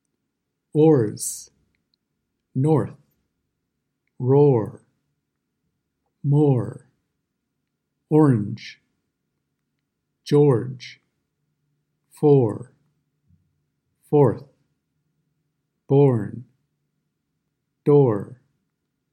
Lesson 5 – “R” vowels /ɝ/, /ɚ/, /ɑr/, /ɜr/, /ɪr/, /ɔr/ – American English Pronunciation
The /ɔr/ sound